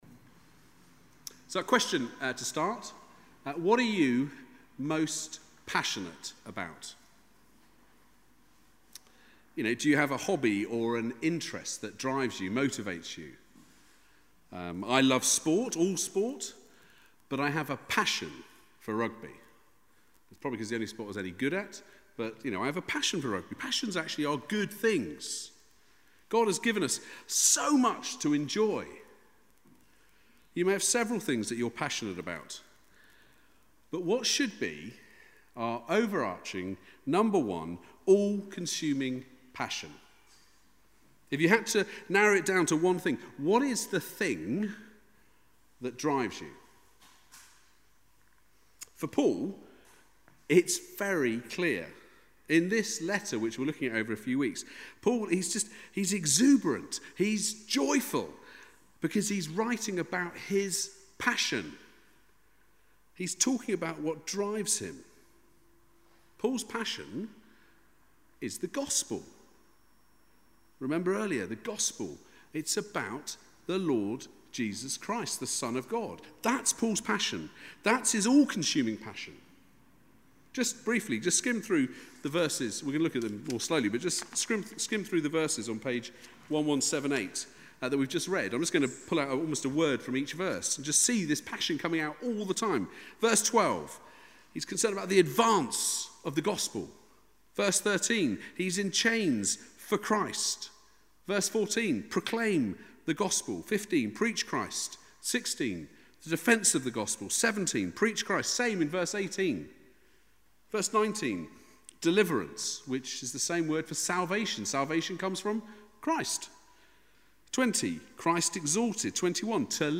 Passage: Philippians 1:12-26 Service Type: Weekly Service at 4pm Bible Text